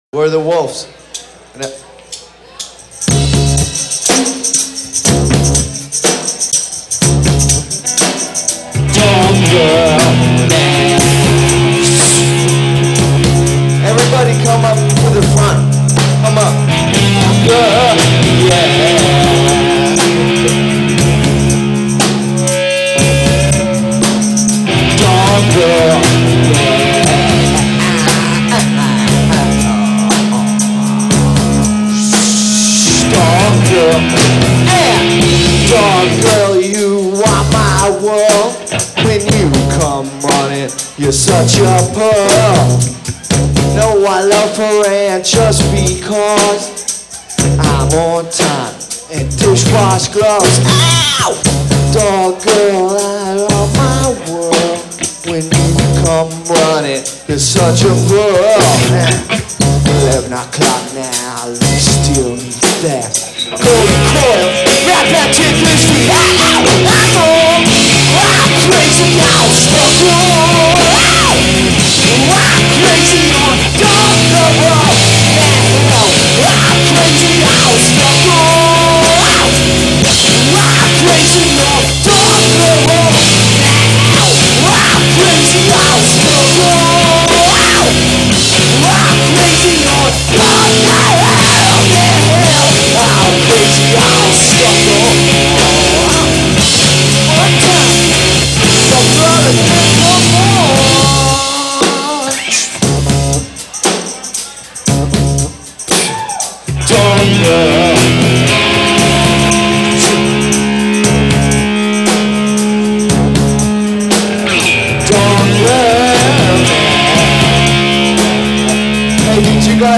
was recorded on adat during a show celebrating
Real dirty show!